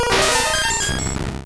Cri de Mew dans Pokémon Rouge et Bleu.